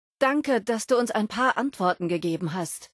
Kategorie:Fallout 76: Audiodialoge Du kannst diese Datei nicht überschreiben.